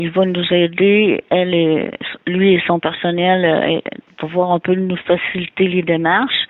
La mairesse de Sainte-Monique, Denis Gendron, a résumé comment son homologue apporte son aide.